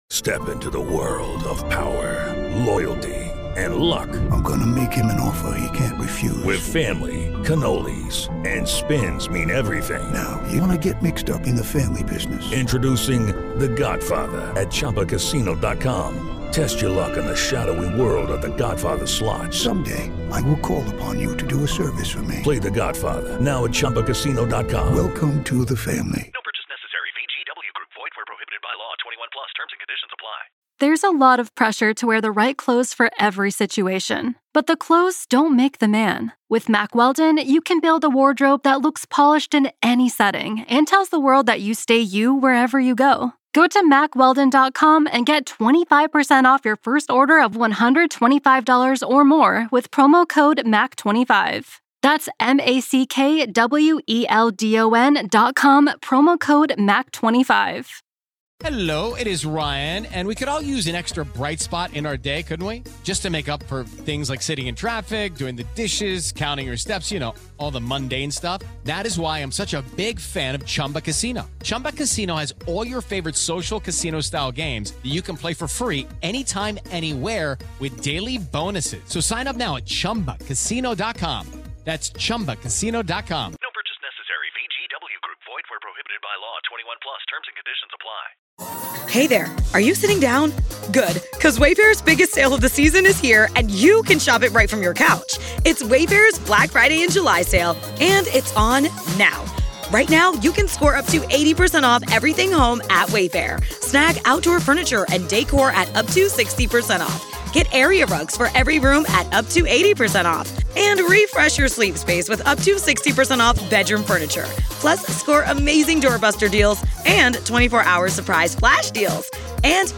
The conversation explores the phenomenon of false confessions, the impact of coercive interrogation tactics, and the psychological effects of solitary confinement, highlighting the need for a careful reassessment of such cases within the justice system. **Main Points of the Conversation:**